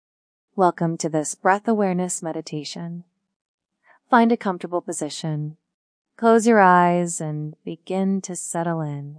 Guided breath awareness meditation. Follow gentle voice cues to deepen your attention on each inhale and exhale, cultivating present-moment awareness.